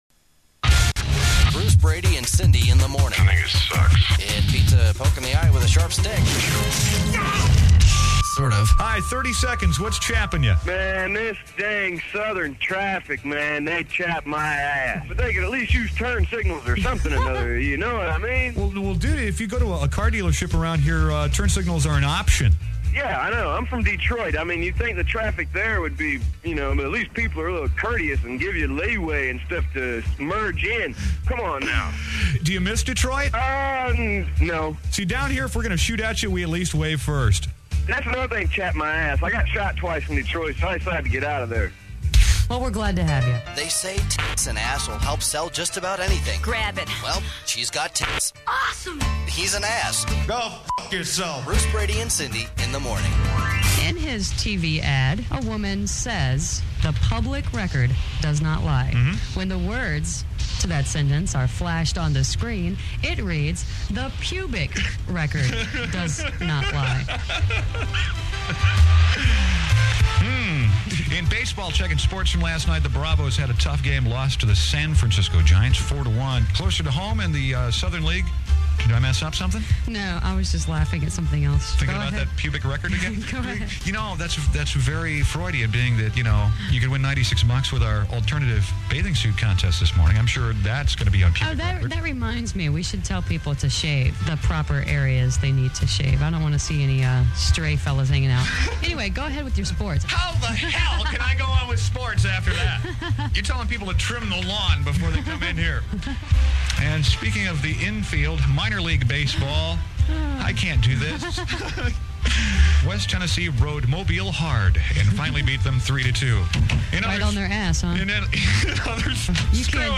Air Talent Demo